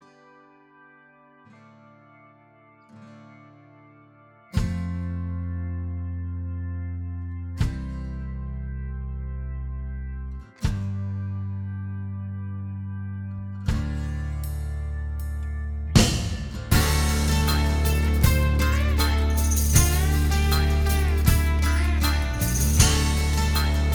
Minus All Guitars Rock 5:37 Buy £1.50